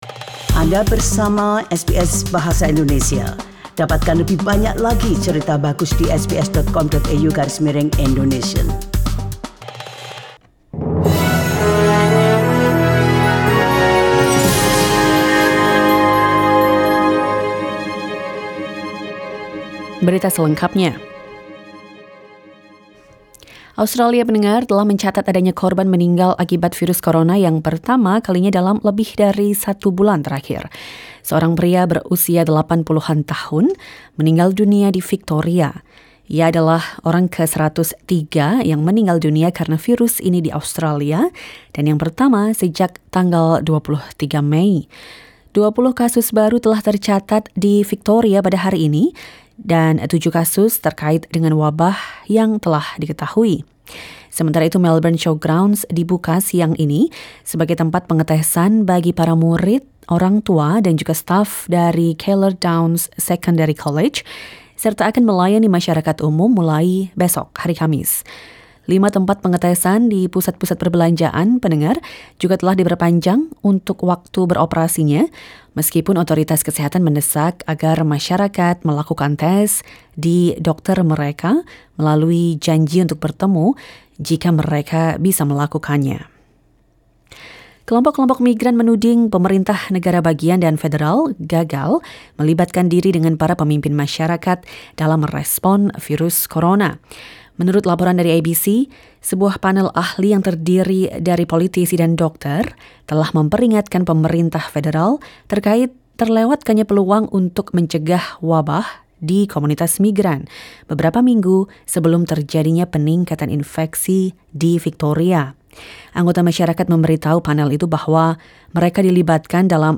SBS Radio News in Bahasa Indonesia - 24 June 2020